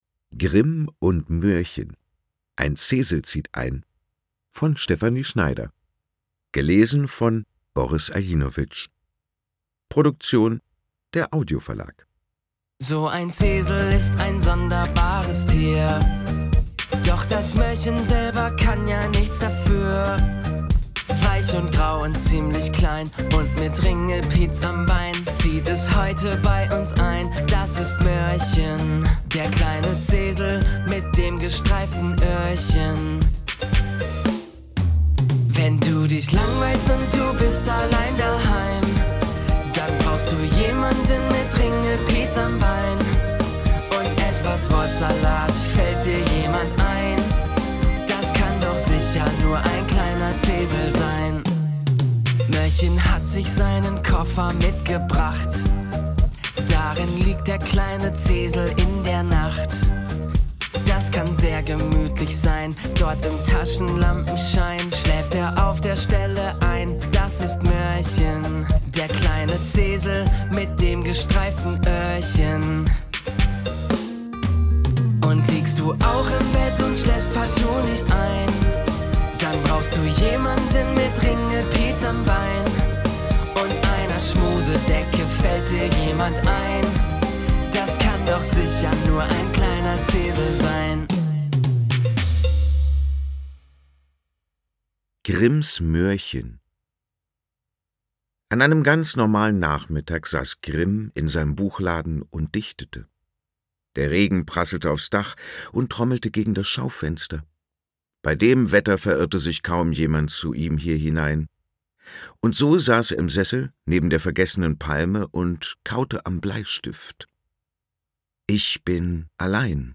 Lausche dem Hörbuch: Höre dir einen Auszug aus ›Grimm und Möhrchen – Ein Zesel zieht ein‹ an: Ihr Browser kann diese Datei nicht wiedergeben.
Hoerprobe_Ein_Zesel_zieht_ein.mp3